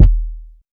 KICK.21.NEPT.wav